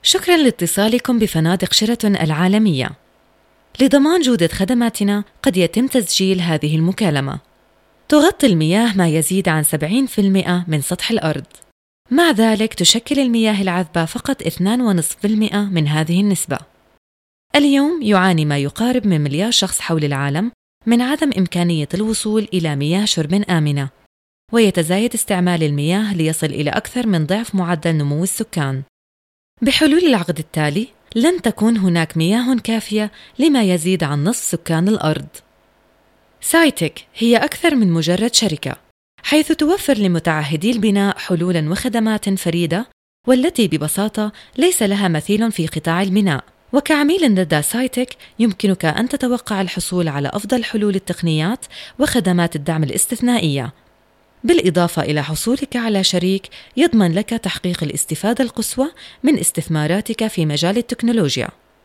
Professional, Warm, Fresh, Believable, clear, lively, Strong, Deep
Sprechprobe: Werbung (Muttersprache):